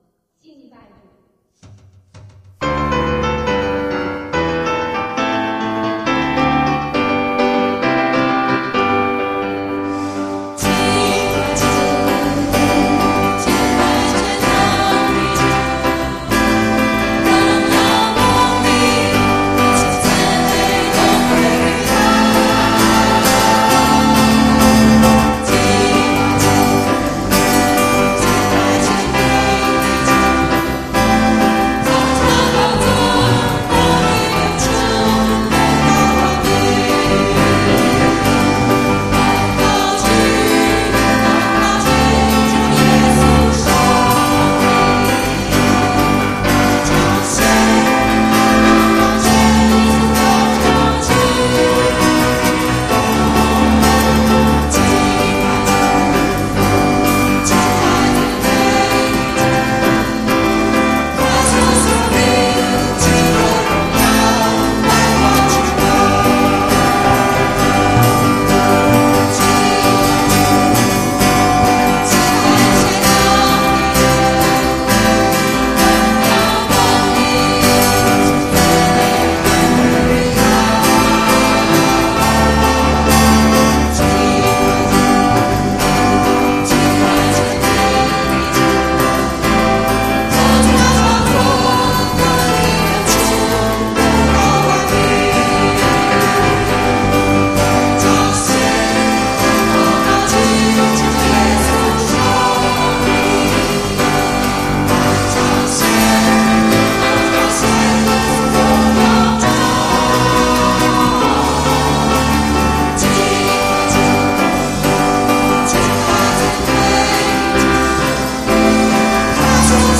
第二次敬拜赞美祷告会，伴奏技术、歌唱技术、音响与录音技术都比较欠缺。